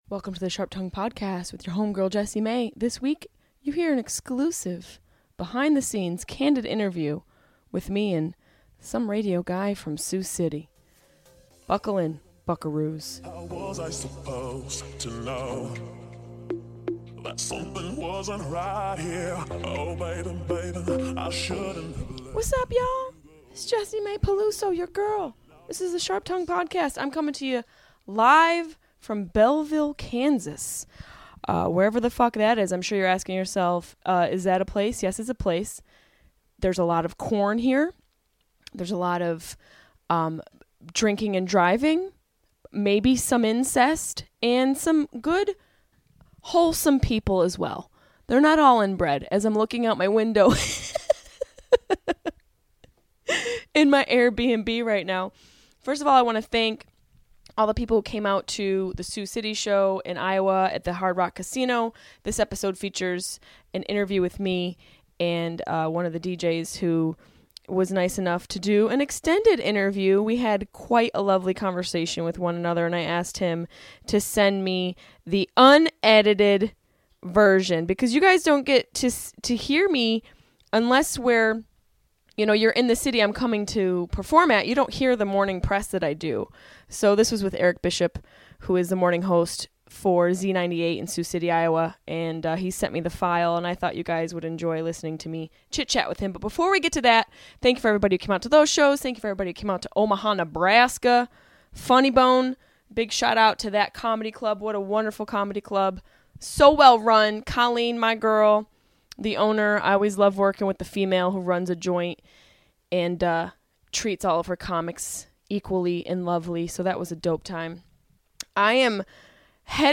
#96 Interview with a Trampire